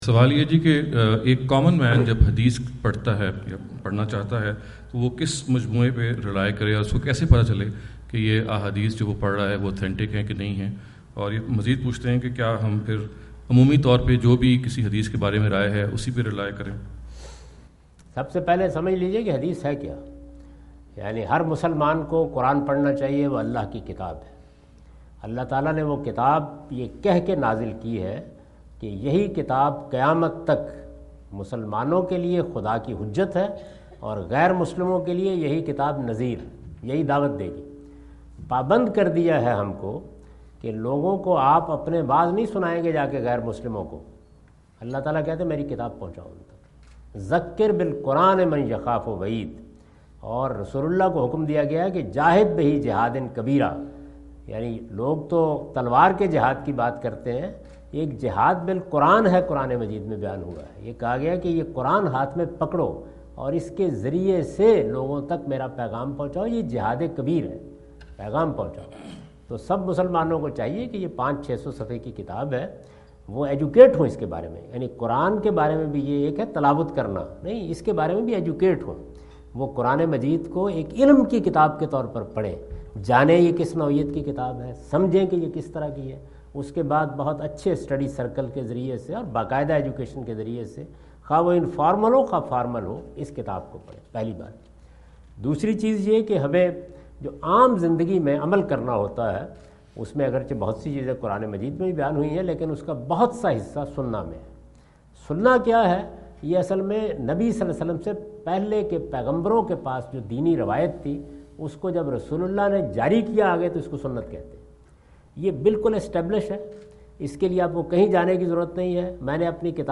Javed Ahmad Ghamidi answer the question about "How to determine authenticity of a hadith report?" During his US visit in Dallas on October 08,2017.